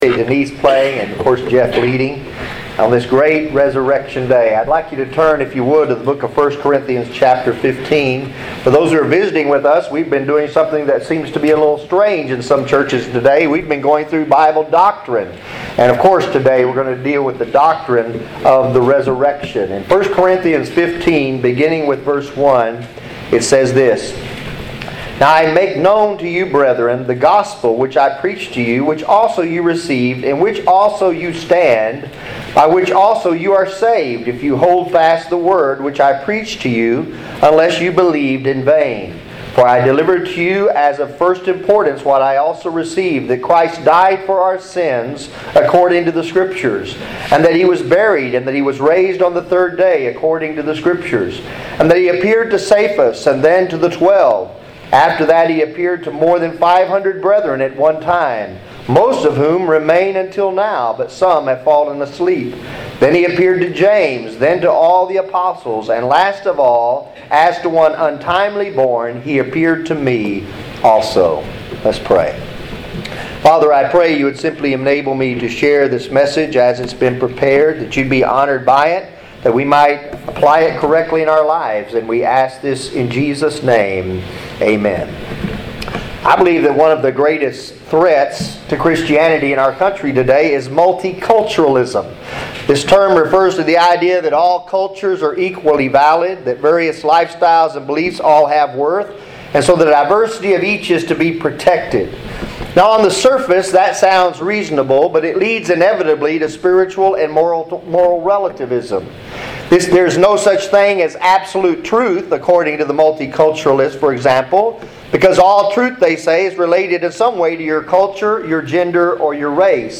Sermons | Providential Baptist Church